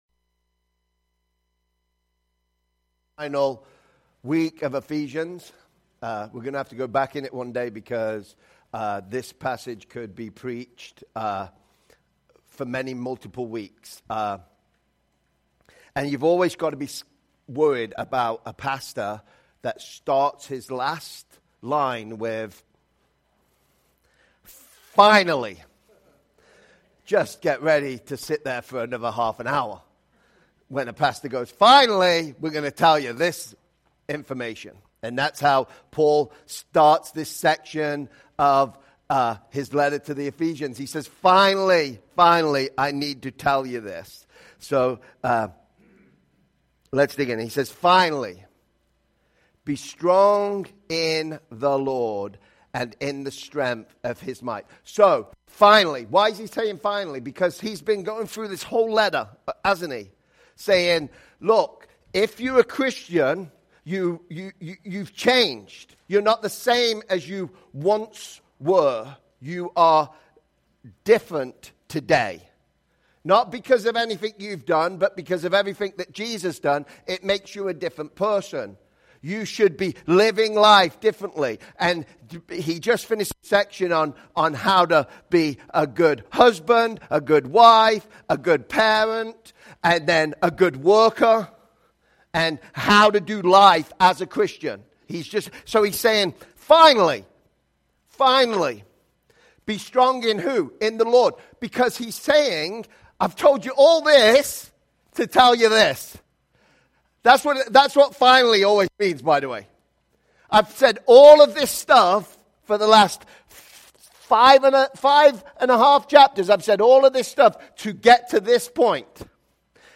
Sermons by Life815